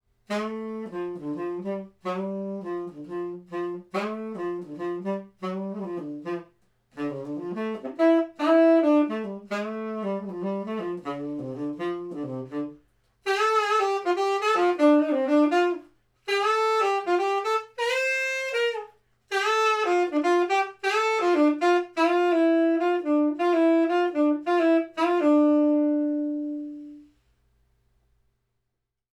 These are the raw Mid and Side files.
Side Sax
Side_Sax.wav